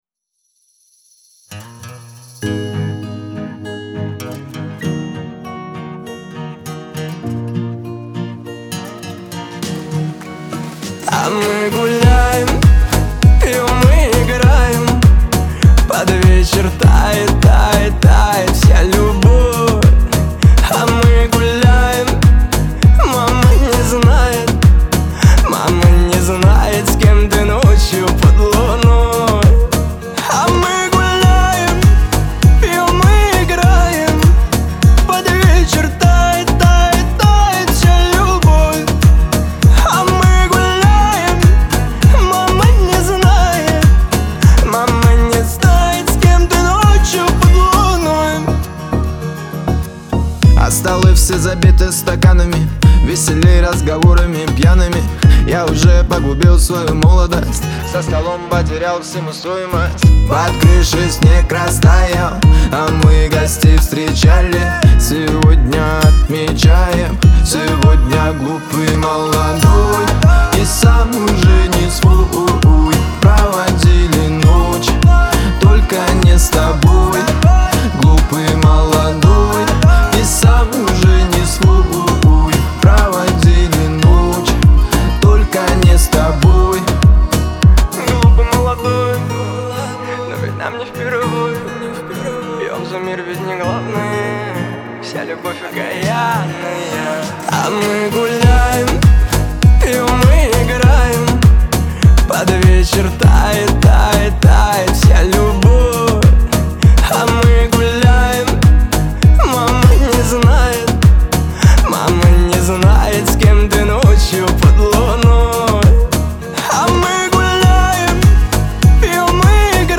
Танцевальная музыка
dance песни